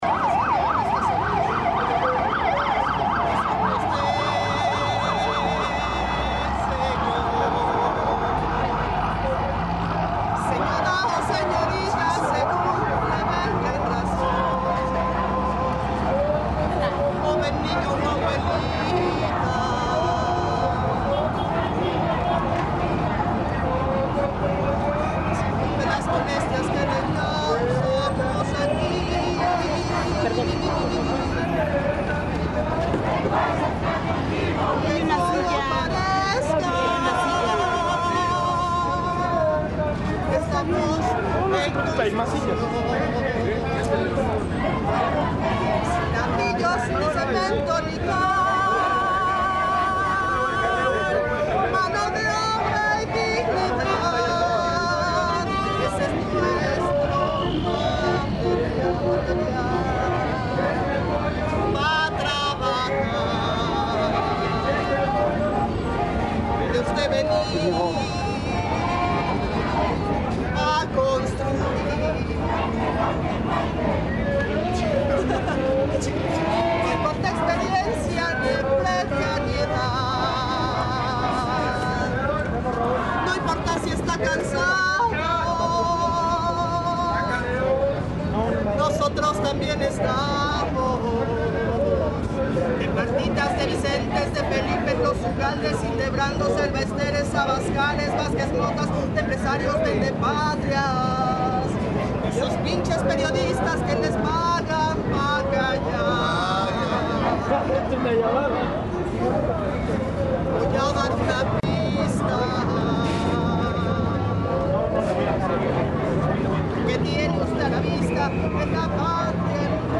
Iba pasando por Reforma y Bucareli.
El audio está bastante defectuoso porque, también afortunadamente, fue grabado mientras el tráfico corría alrededor de las personas que gritaban "voto x voto, casilla x casilla".